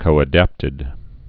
(kōə-dăptĭd)